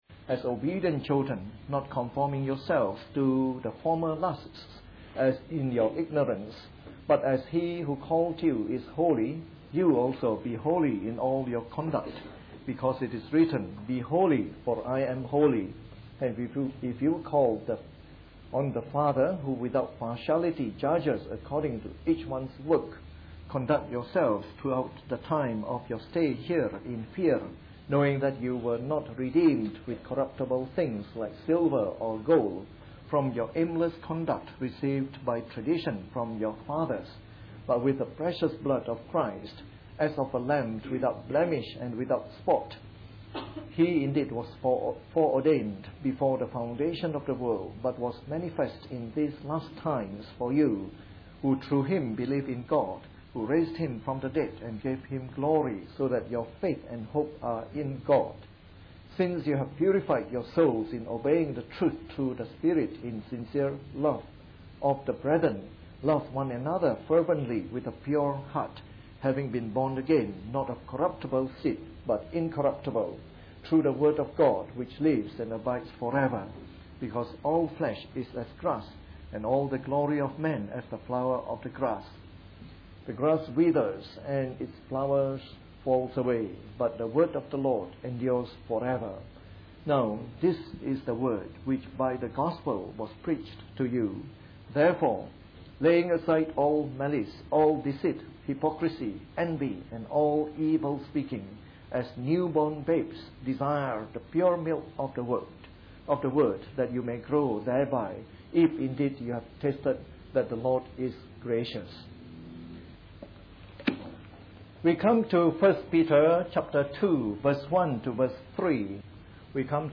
Part of our new series on “The Epistles of Peter” delivered in the Evening Service.